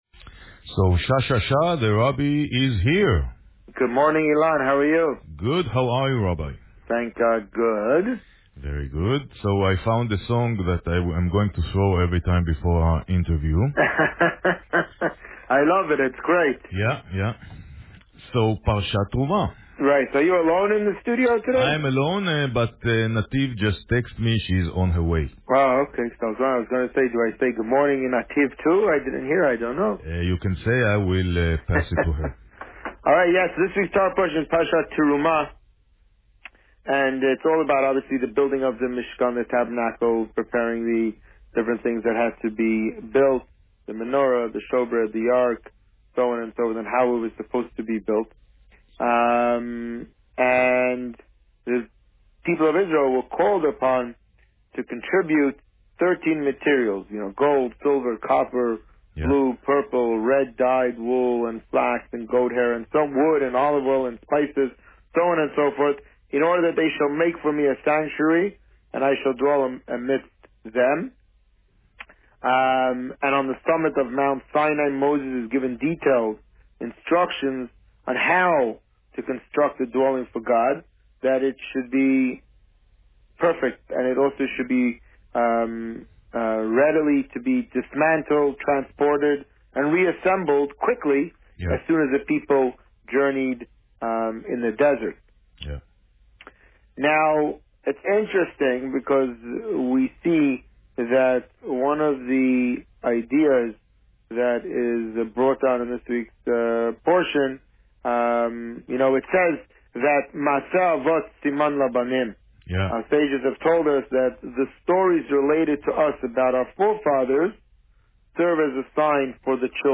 This week, the Rabbi spoke about Parsha Terumah and the upcoming Purim party. Listen to the interview here.